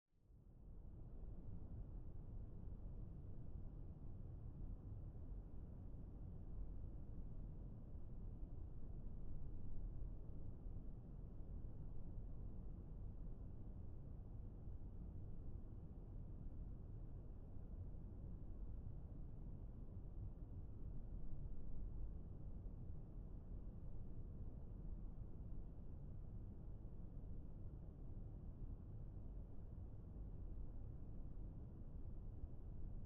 Black Noise, The Sound Of Sound Effects Free Download